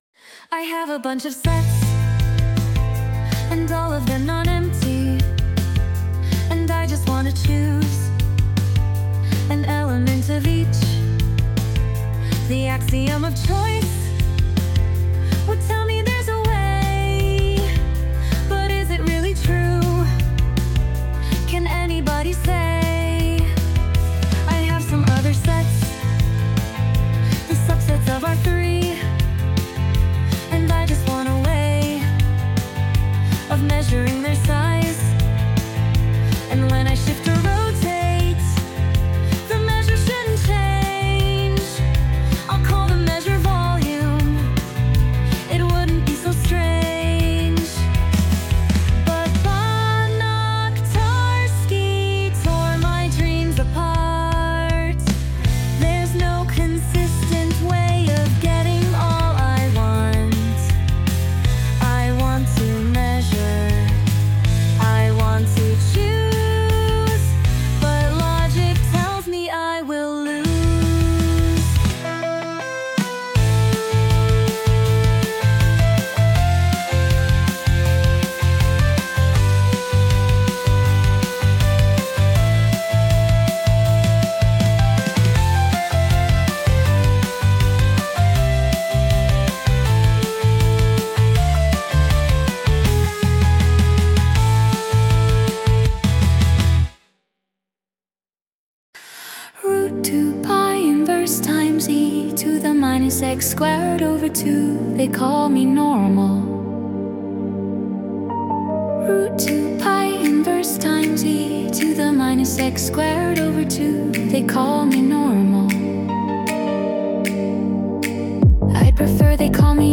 Warmup: Warm-up song zip or single mp3 aided by local AI